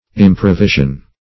Search Result for " improvision" : The Collaborative International Dictionary of English v.0.48: Improvision \Im`pro*vi"sion\, n. [Pref. im- not + provision.]